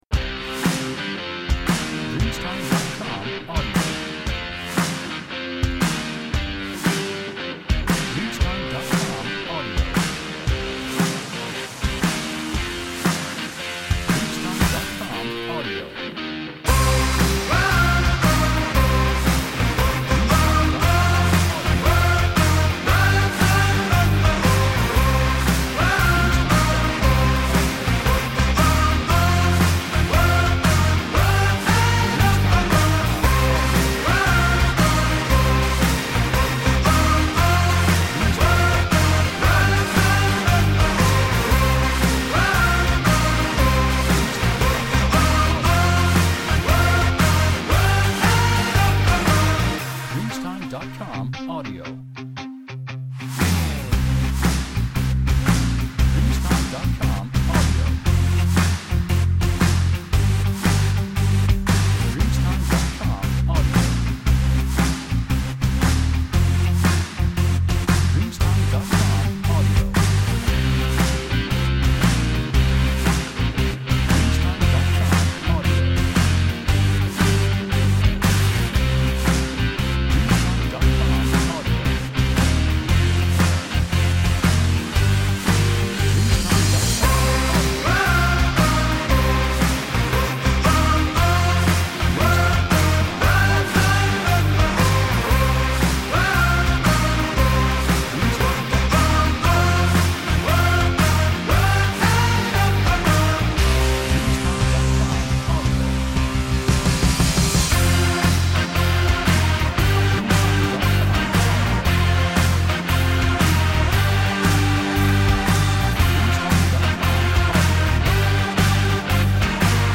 Energetic Indie Rock [Full]